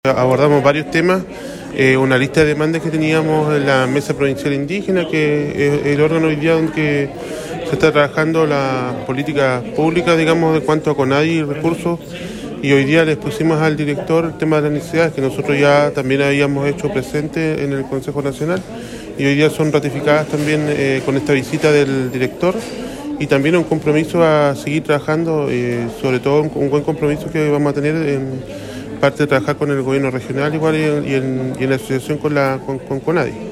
Mario Inai, Consejero Nacional Indígena ante Conadi; explicó que durante la visita de Luis Penchuleo se pudieron tomar distintos compromisos para subsanar necesidades de las comunidades, que ya habían sido presentadas anteriormente en el Consejo Nacional.